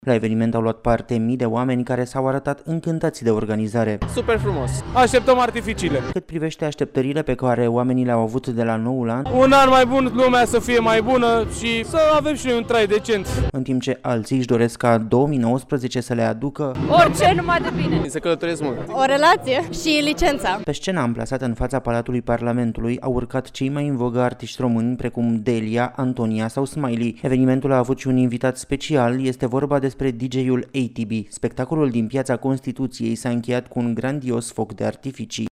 Sute de mii de români au petrecut noaptea de Anul Nou în stradă, la evenimentele organizate în marile oraşe. La Bucureşti, în Piaţa Constituţiei, a avut loc un concert organizat de Primăria Capitalei.